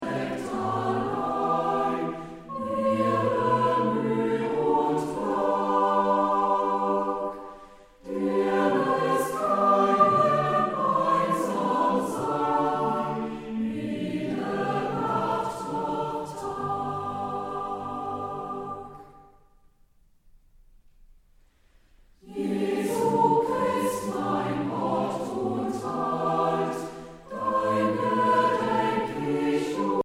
begleitet von Instrumentalisten.